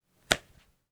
Book Open.wav